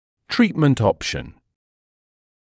[‘triːtmənt ‘ɔpʃn][‘триːтмэнт ‘опшн]вариант лечения